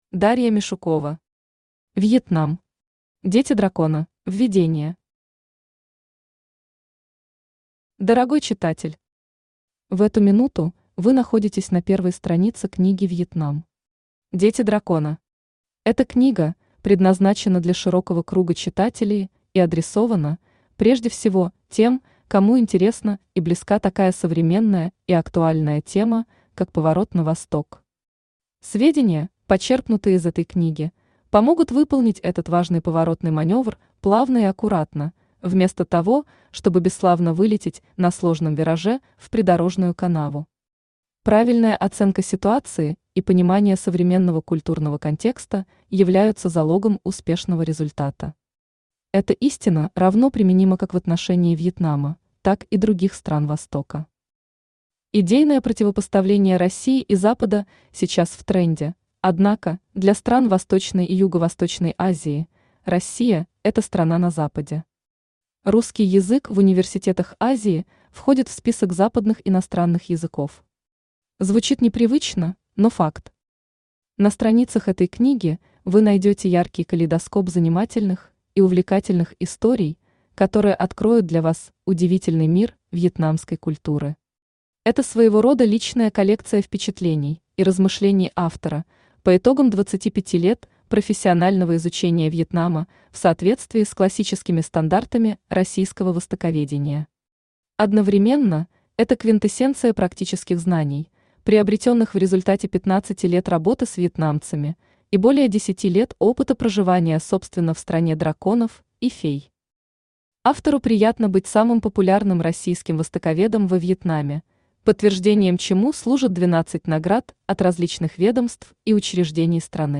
Читает: Авточтец ЛитРес
Аудиокнига «Вьетнам. Дети дракона».